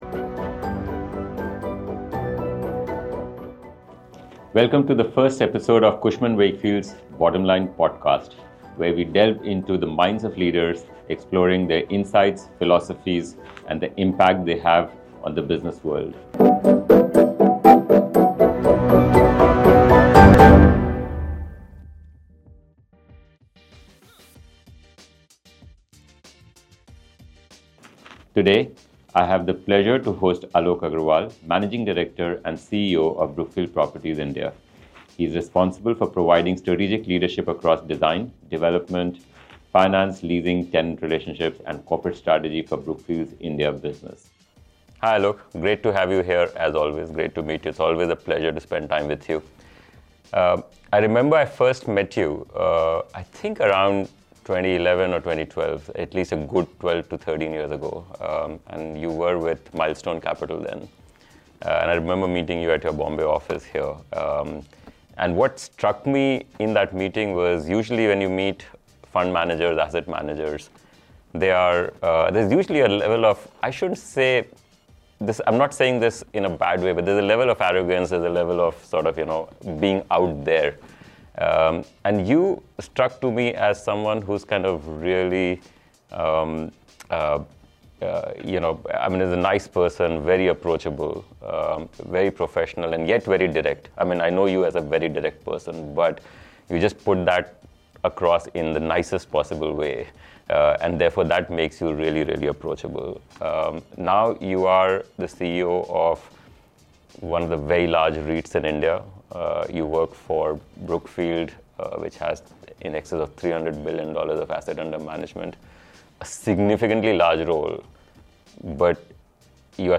an unfiltered conversation on leadership, resilience, and the future of real estate